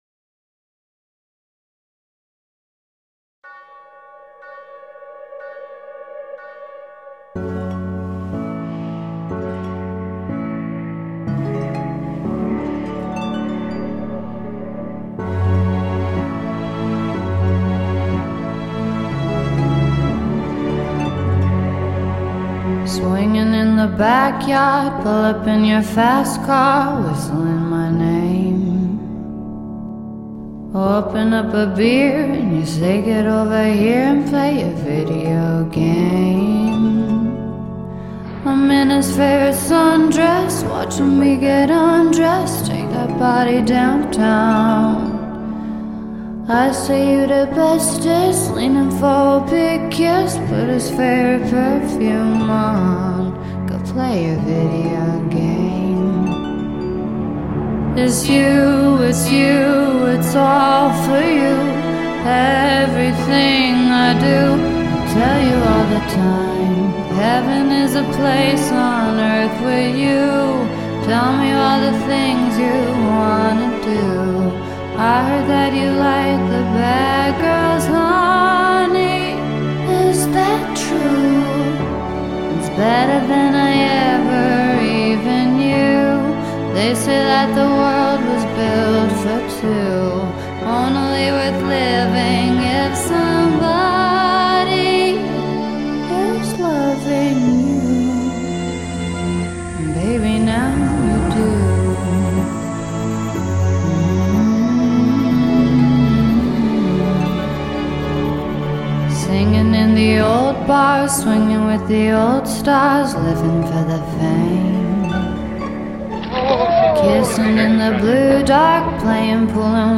And this one is so sad and beautiful.